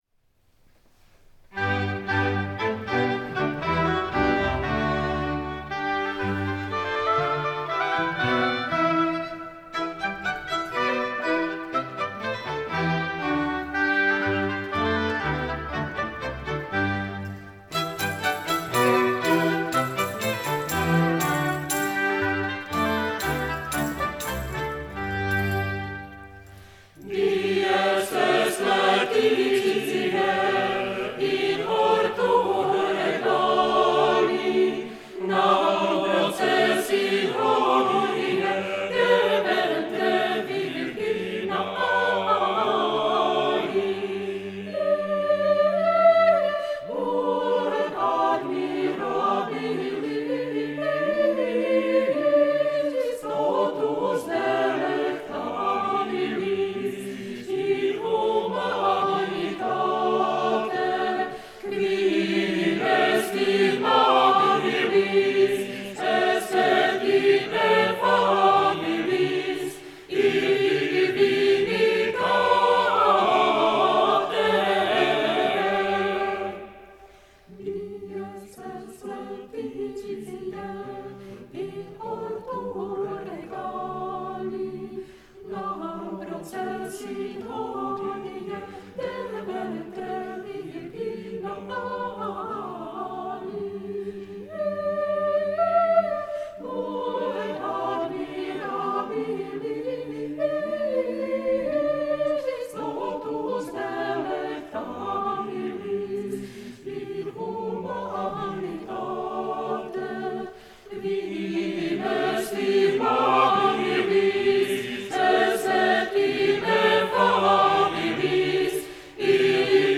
The list origins from concerts performed from 1971.